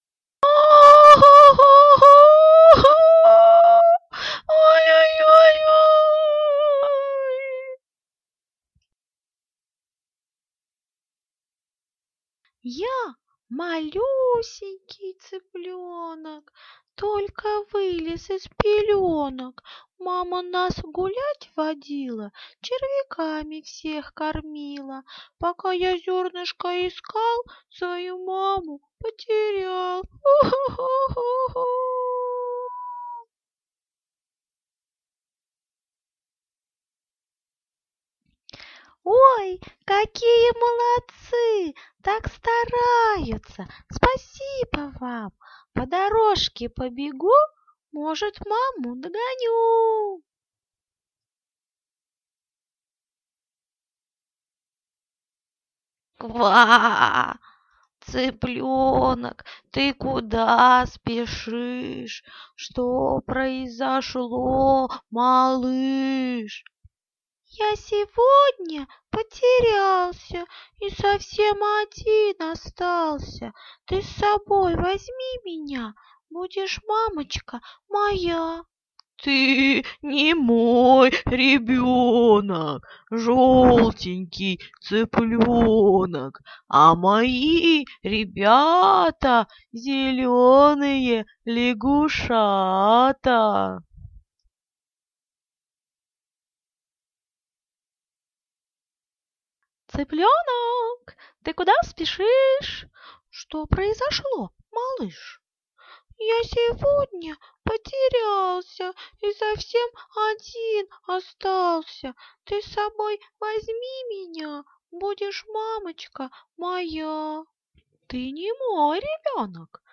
Данный кукольный спектакль созданн в программе АУДАСИТИ, можно использовать как сюрпризный момент на празднике 8 марта, или как отдельный спектакль.